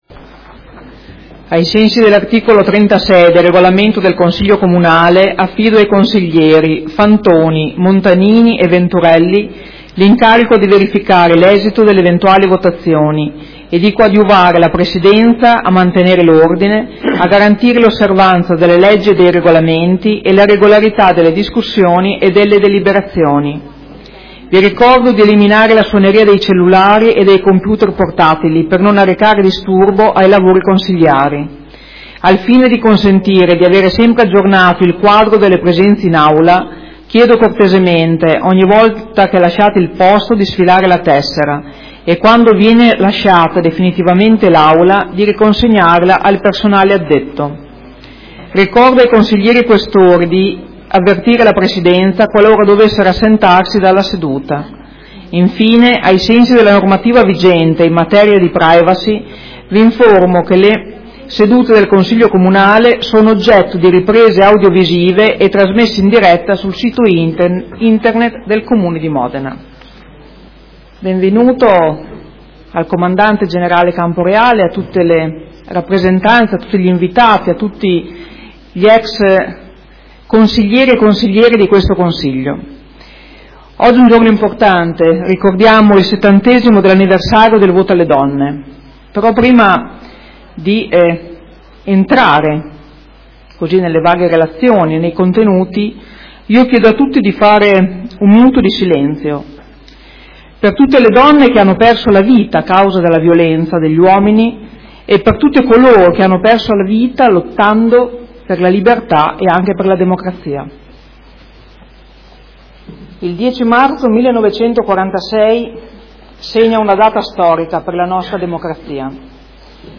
Seduta del 30/05/2016.